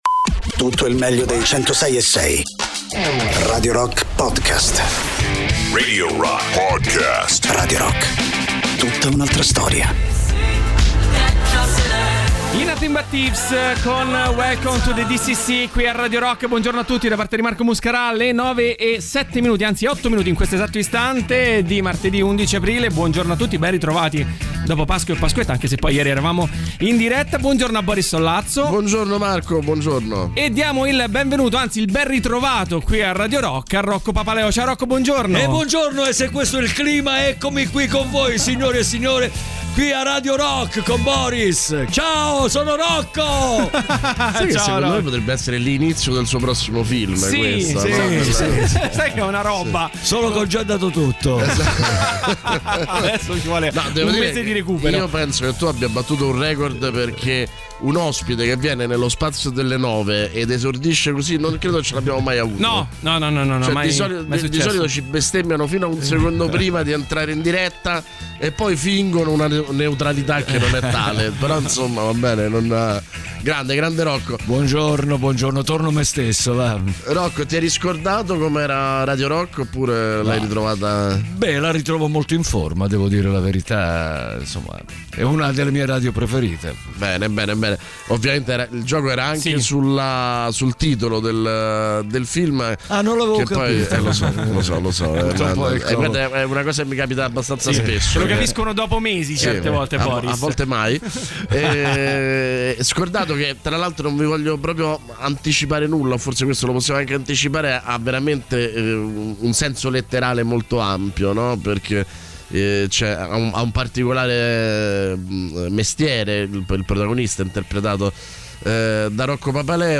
Interviste: Rocco Papaleo (11-04-22)